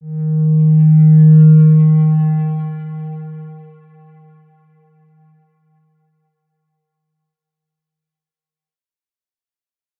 X_Windwistle-D#2-pp.wav